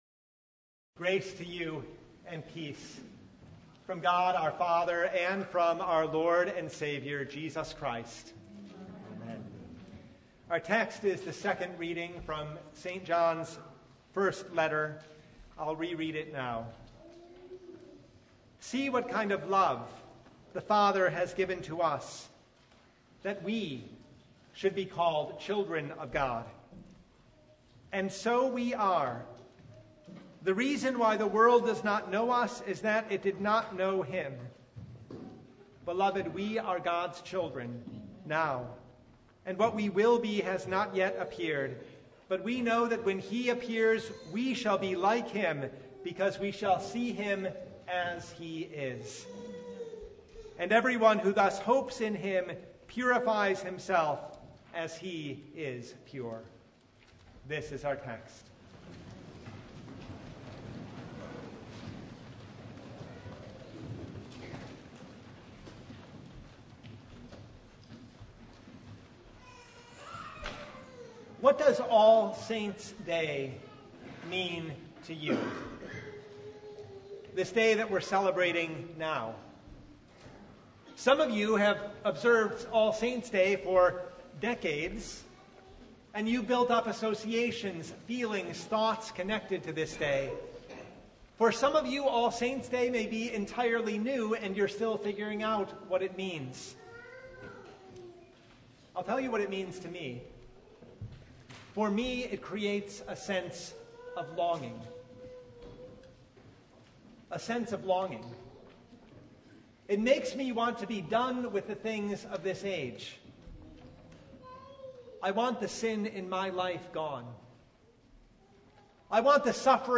Service Type: The Feast of All Saints' Day